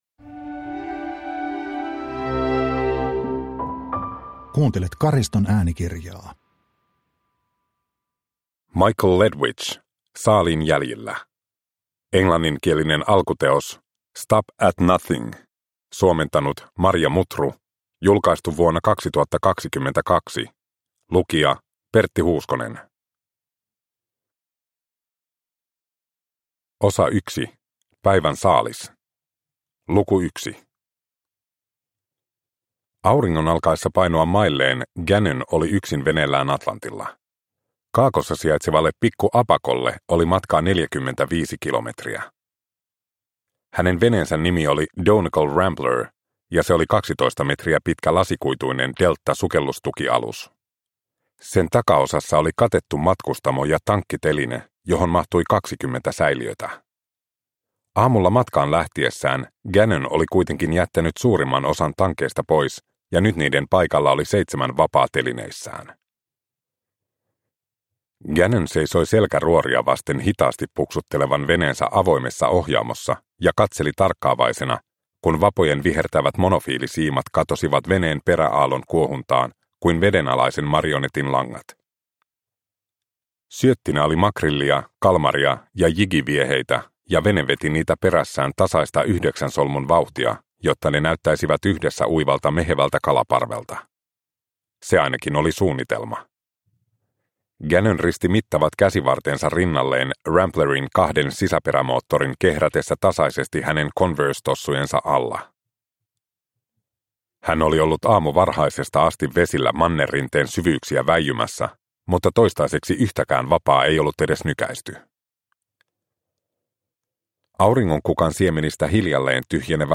Saaliin jäljillä – Ljudbok – Laddas ner